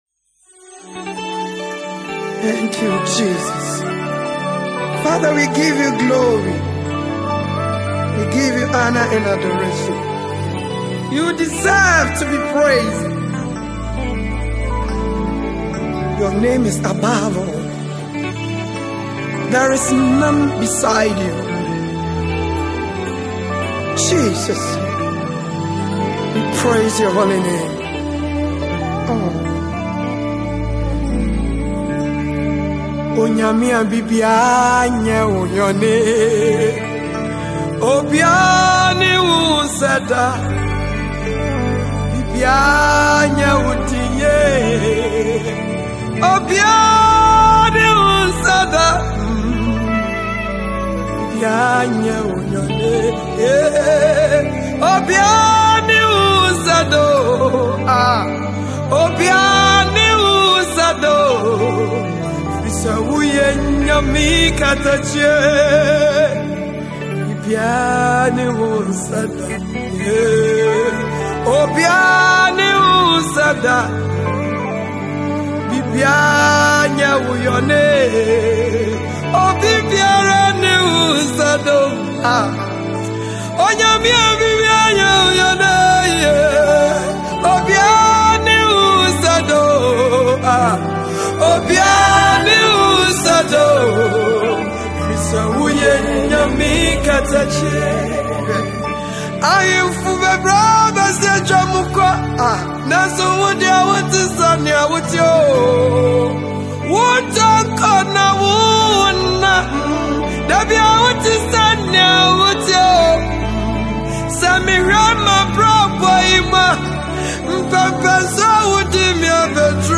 Gospel
a powerful worship song